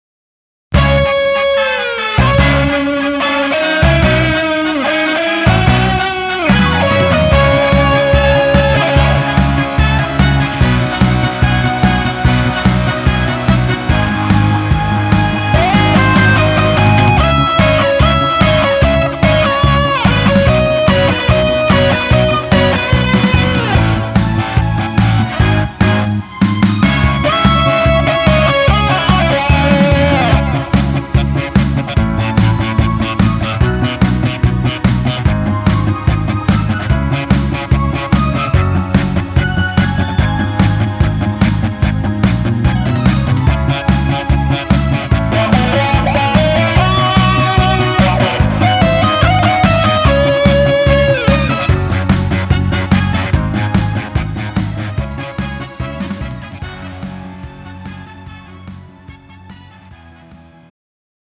음정 -1키
장르 가요 구분 Pro MR